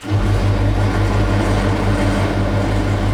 grind.wav